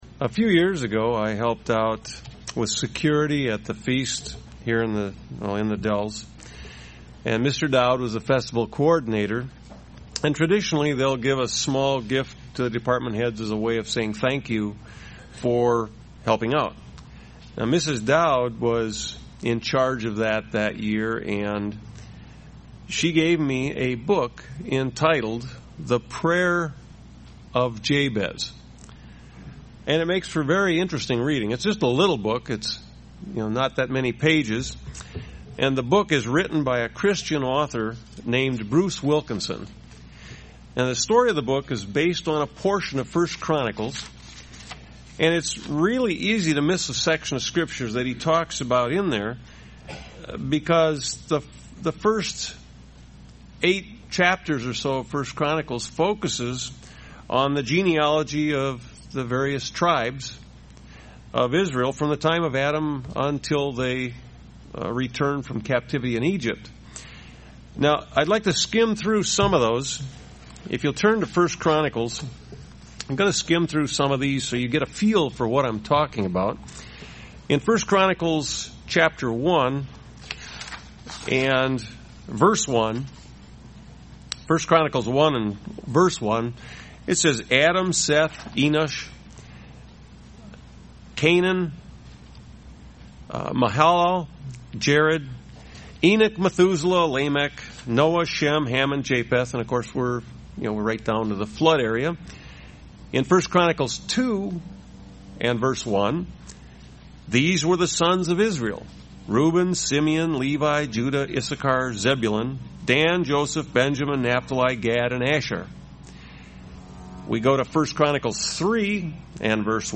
UCG Sermon Studying the bible?
Given in Beloit, WI